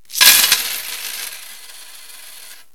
cashcheck.wav